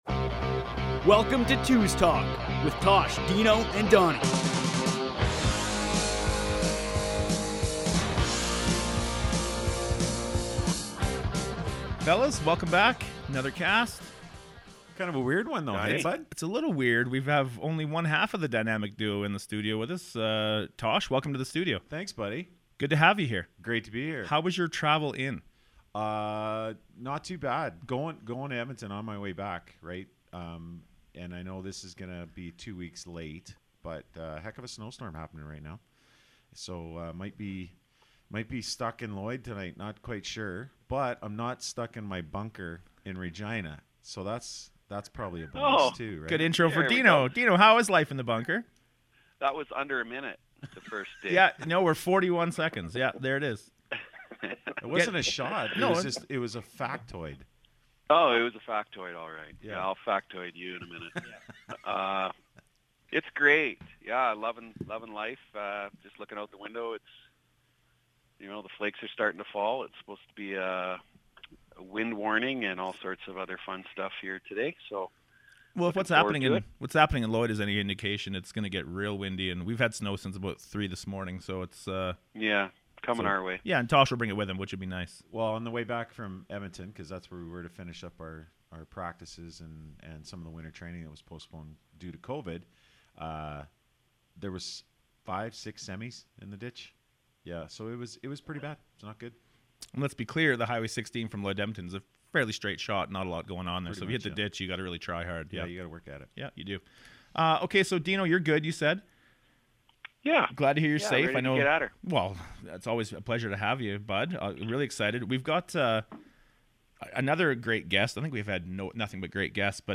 As always lots of laughs and stories.